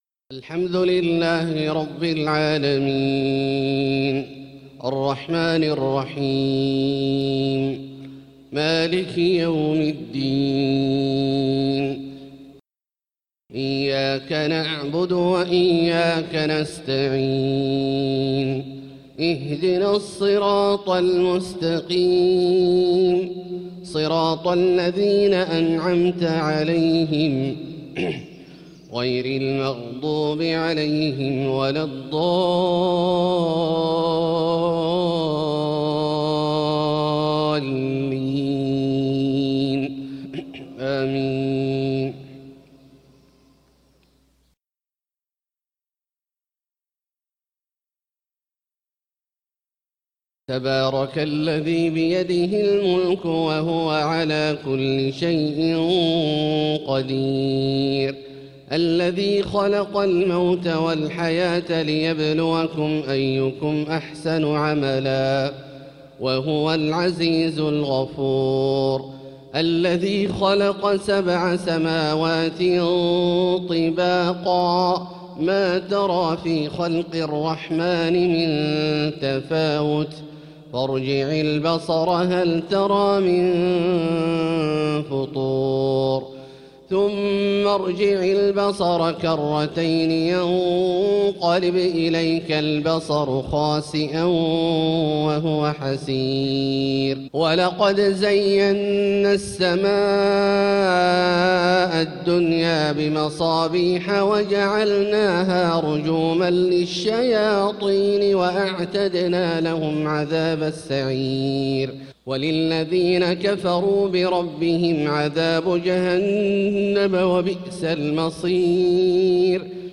صلاة الفجر للقارئ عبدالله الجهني 7 شعبان 1445 هـ